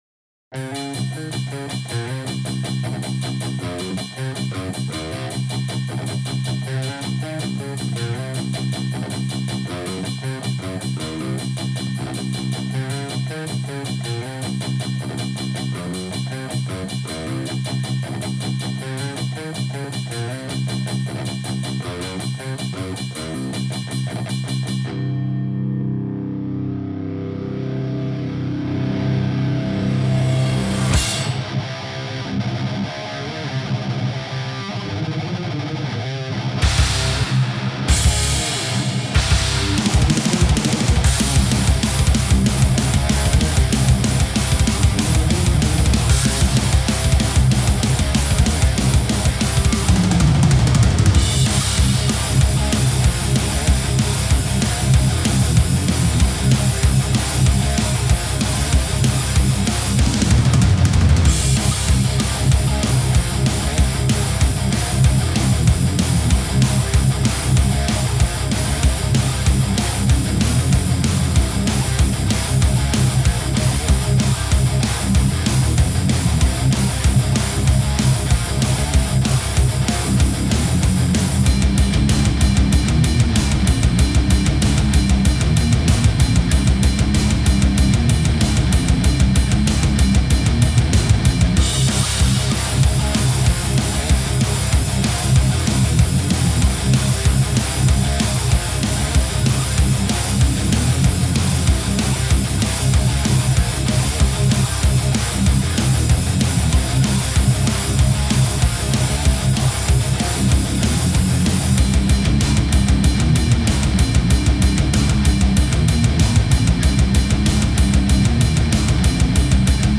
the lead and overdubs were done with guitar rig 2 presets for simplicity's sake. the rhythm guitar is 2 takes; wagner with fredman straight and fredman angled on each take panned 80/100 LEFT and 80/100 right respectively
drums are ezdrummer dkfh addon